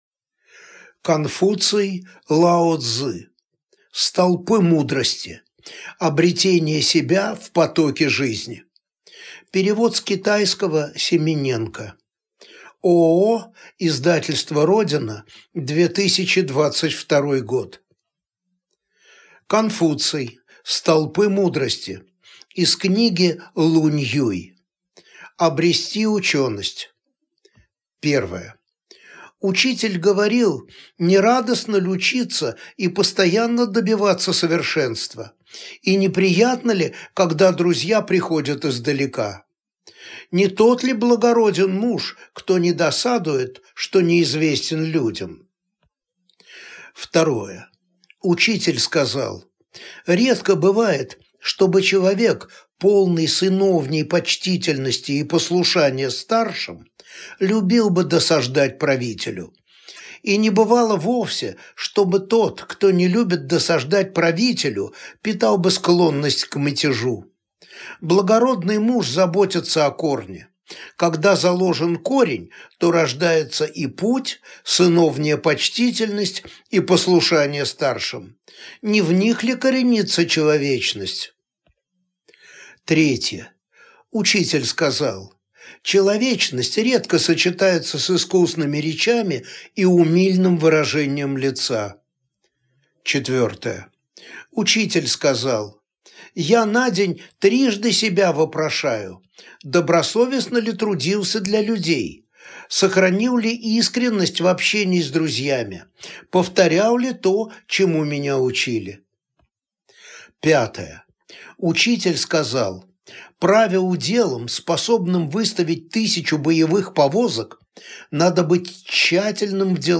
Аудиокнига Столпы мудрости | Библиотека аудиокниг